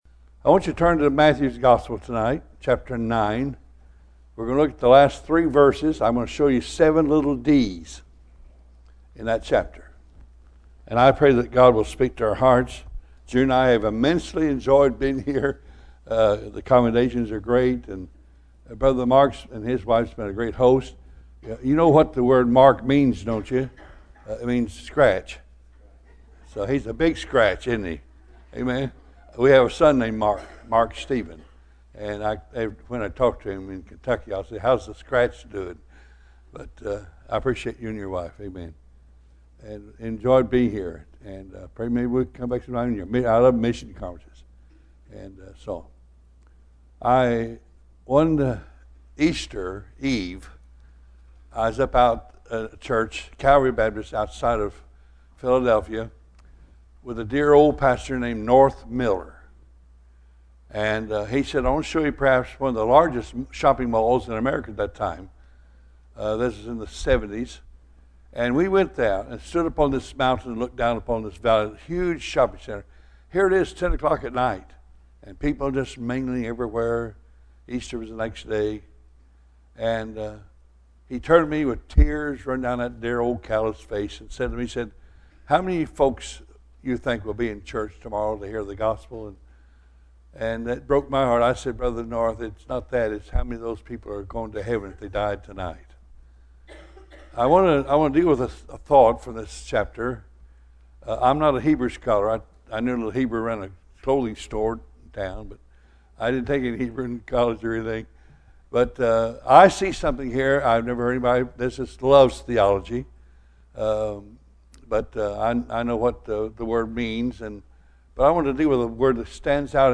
Bible Text: Matthew 9 | Preacher